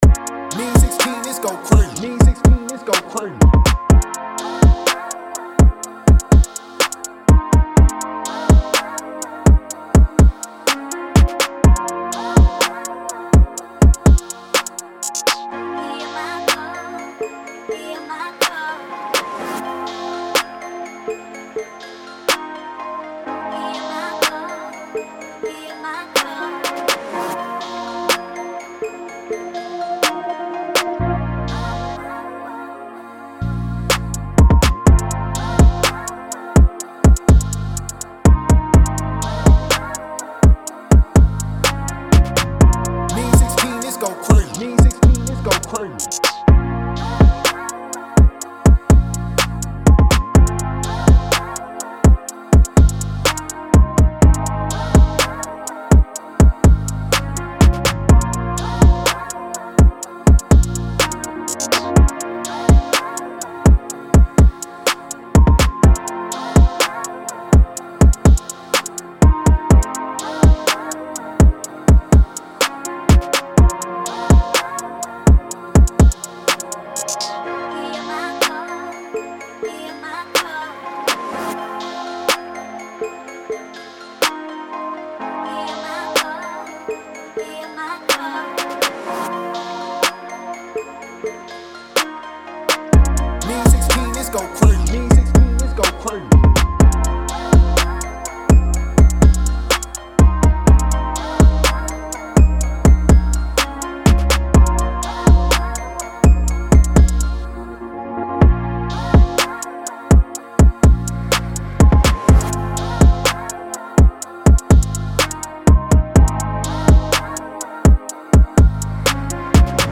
A#-Min 124-BPM